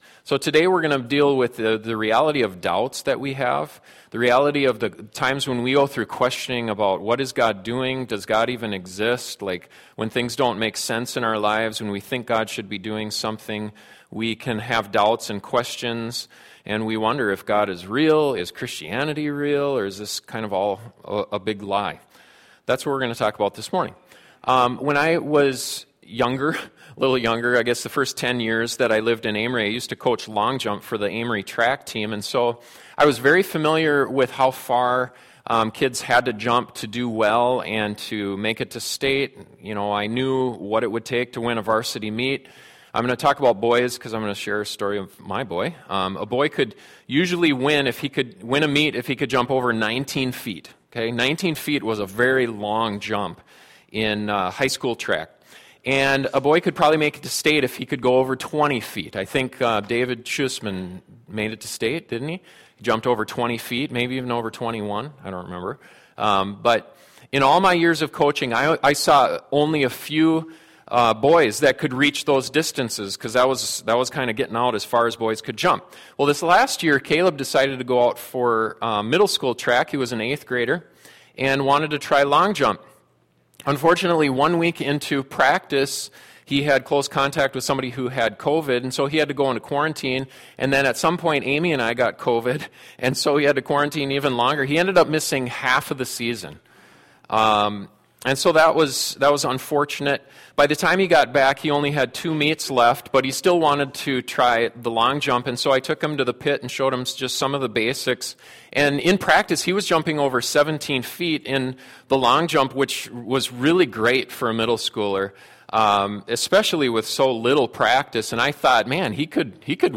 Do you ever have times of doubt, when you wonder if God is real and question whether or not Christianity is true? This sermon gives direction for what to do and where to turn during those times.